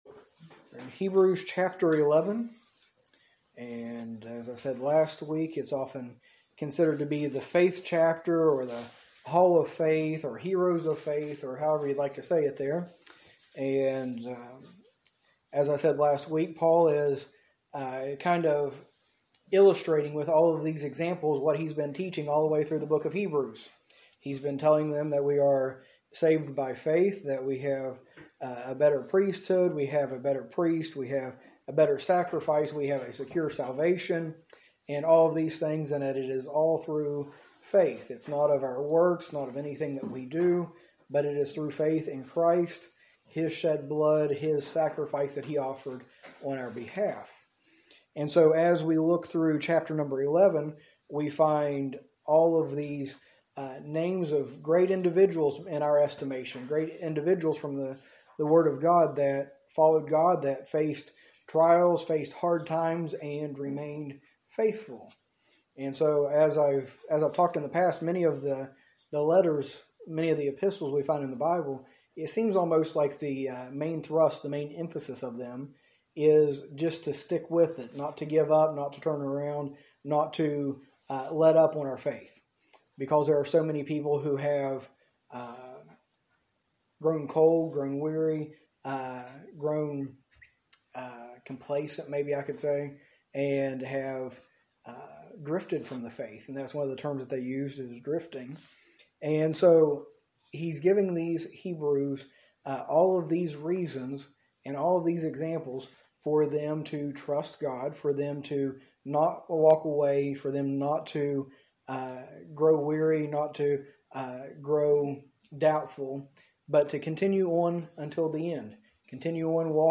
A message from the series "Hebrews."